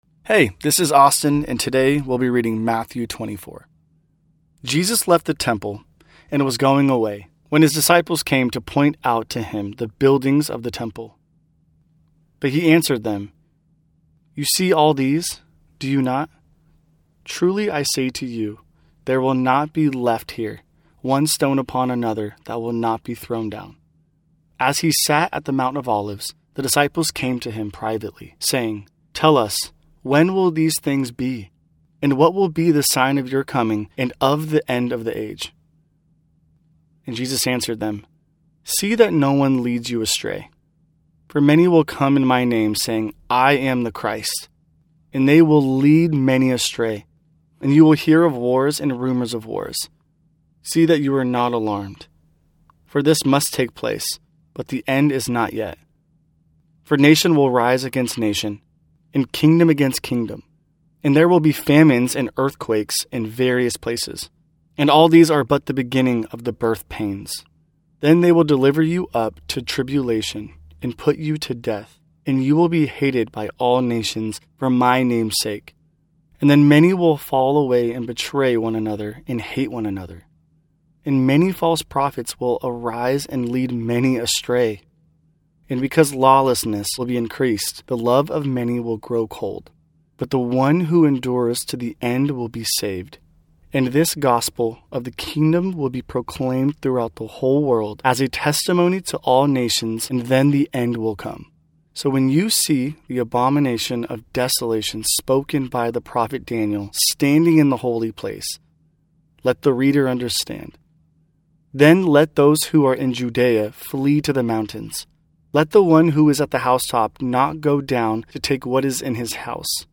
New Testament Bible Reading Plan – Audio Version